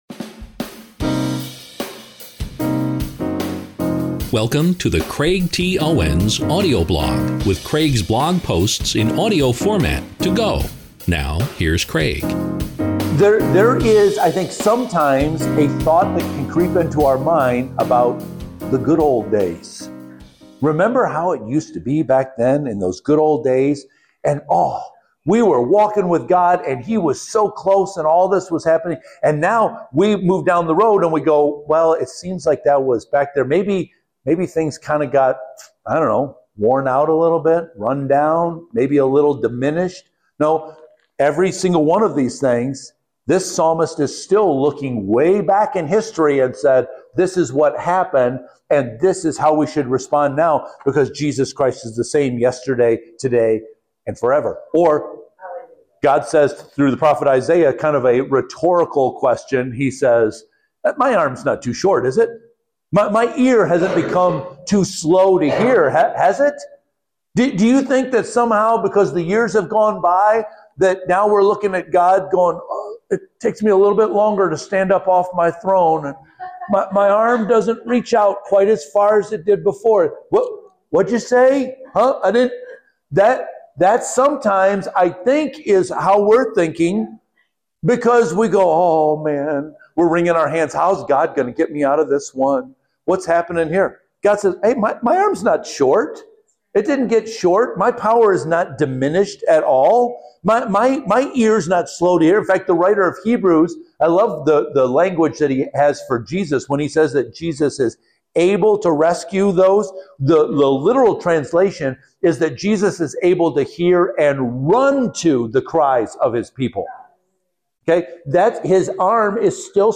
This is a clip from the sermon Review Your History, which was a part of our mini-series called “What to do in a crisis.”